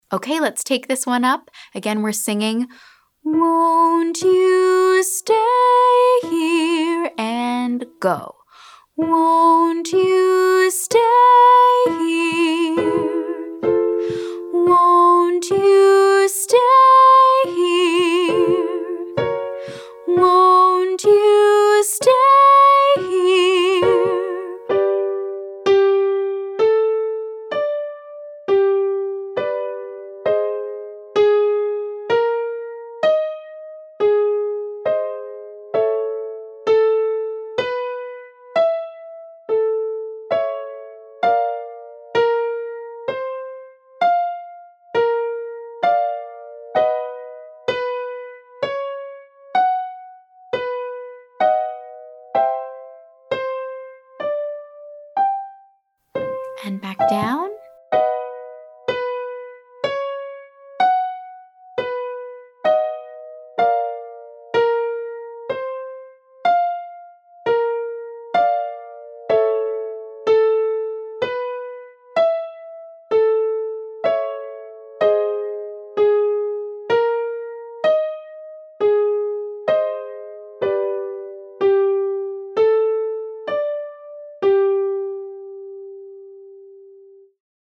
Day 7: High Head Voice Extension
Exercise 3: Won't you Stay here  1 2 5 1